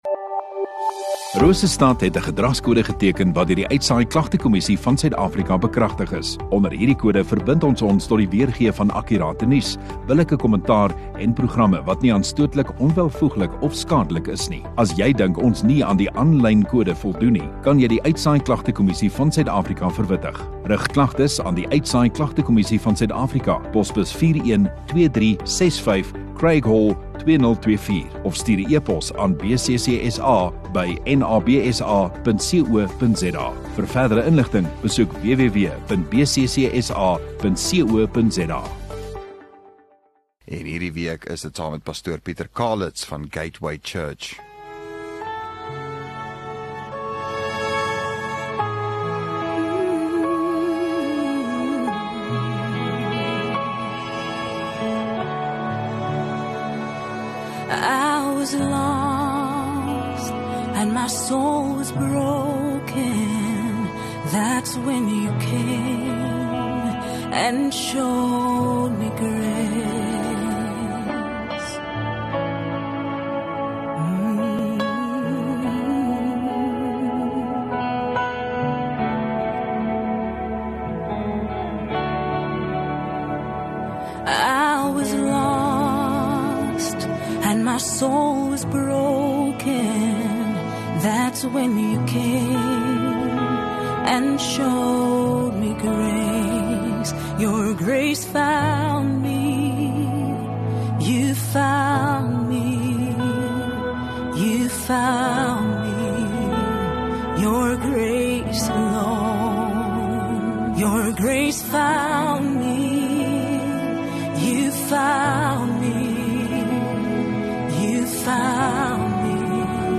4 Jun Woensdag Oggenddiens